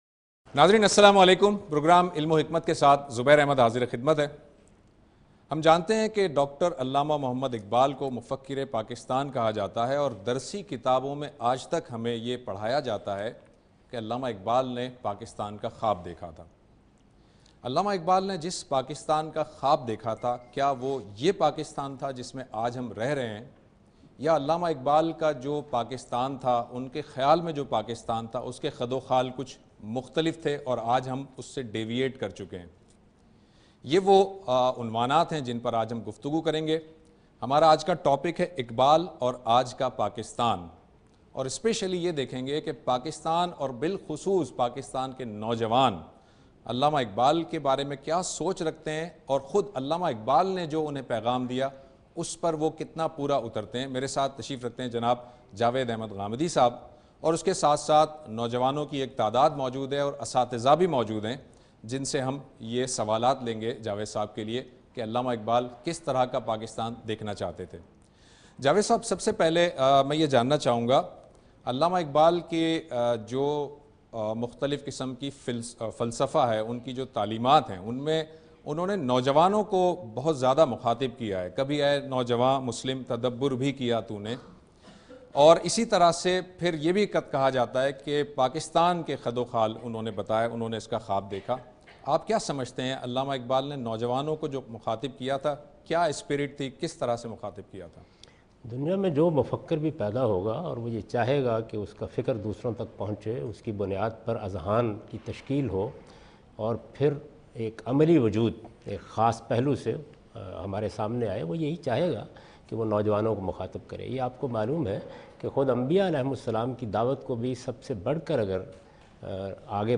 Javed Ahmad Ghamidi speaks and answers questions regarding "Iqbal and Pakistan". (Part-1)